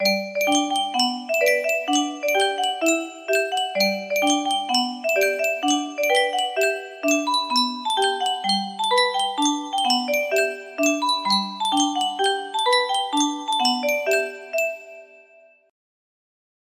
Yunsheng Mousikó koutí - Pentozali 1082 music box melody
Full range 60